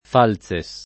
[ f # l Z e S ]